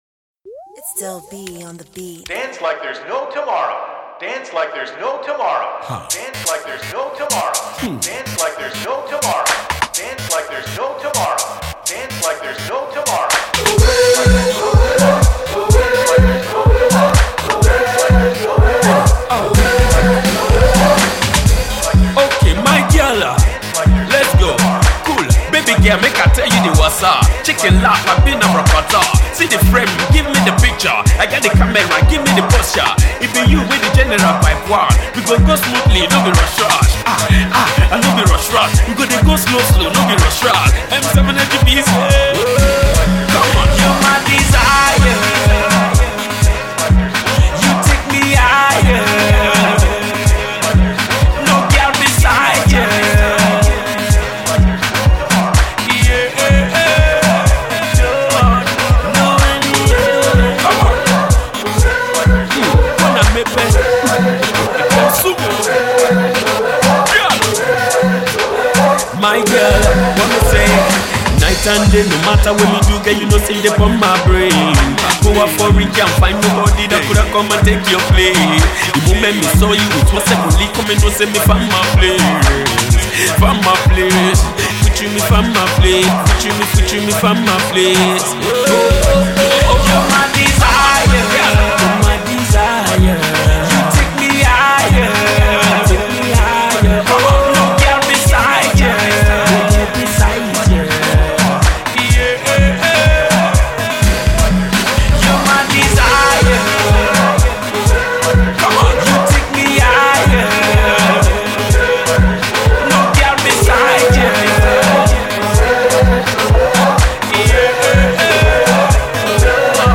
another banger for the clubs